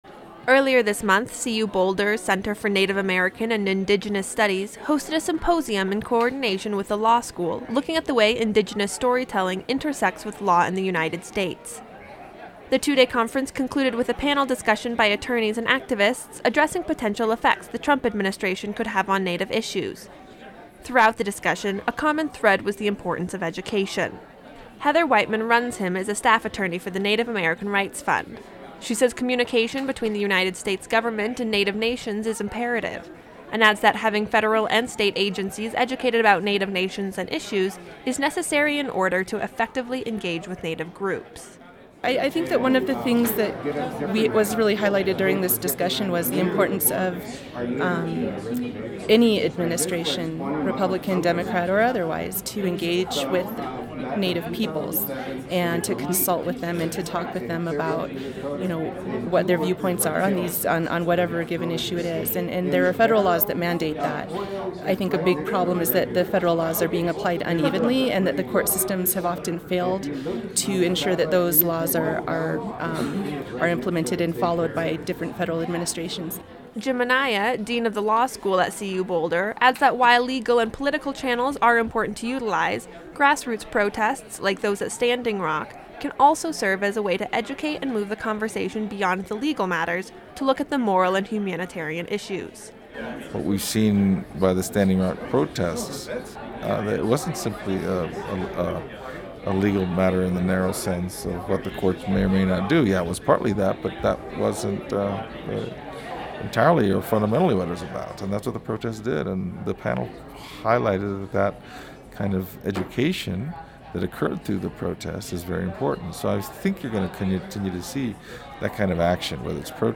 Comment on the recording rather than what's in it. The two day conference concluded with a panel discussion by attorneys and activists addressing potential affects the Trump Administration could have on Native issues.